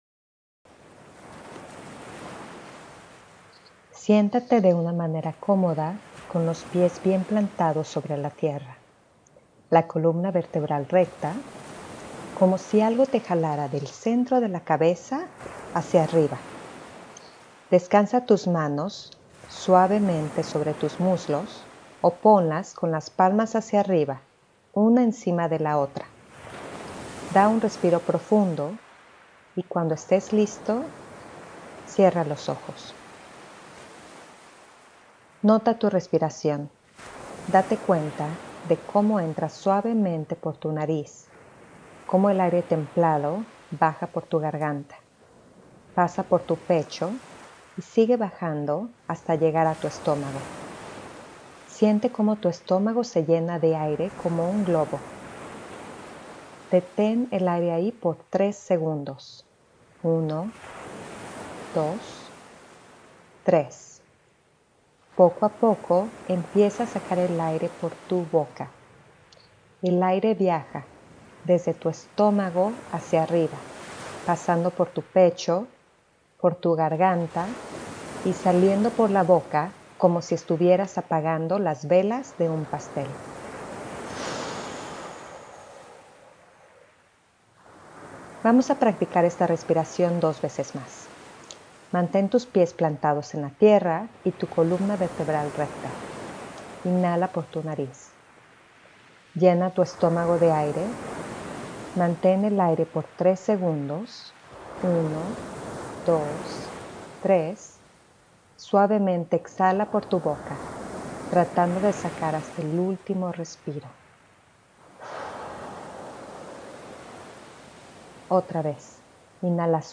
Como un regalo para este primer segmento, les ofrezco una grabación de cinco minutos de respiración y relajación. Esta grabación si tiene una agenda, el ayudarlos a practicar la auto-observación y a reducir el estrés, el cual será tema de otro segmento.
5-minutos-de-relajacion.mp3